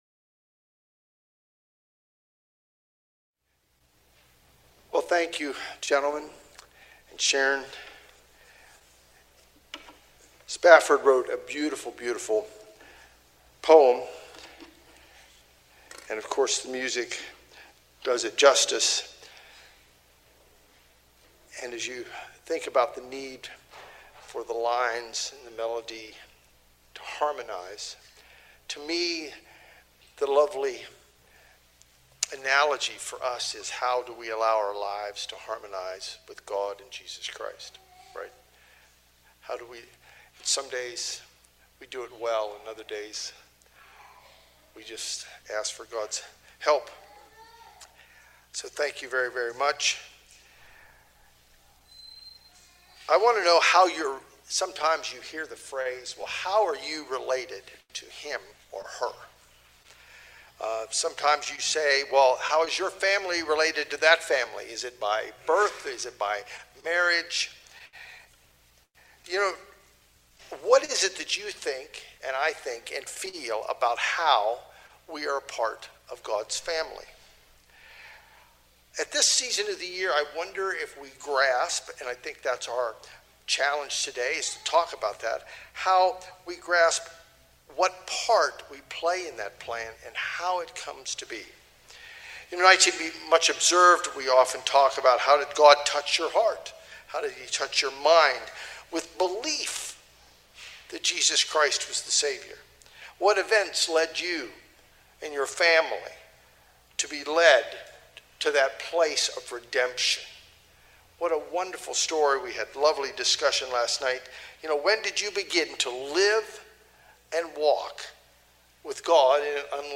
Given on the first Day of Unleavened Bread, we reminded that we have been redeemed through Jesus Christ. His sacrifice on our behalf changes our status with God.
Sermons